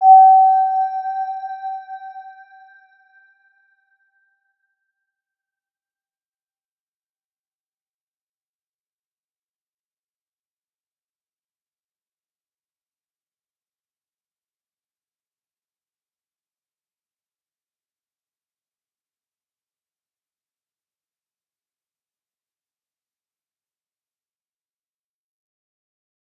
Round-Bell-G5-p.wav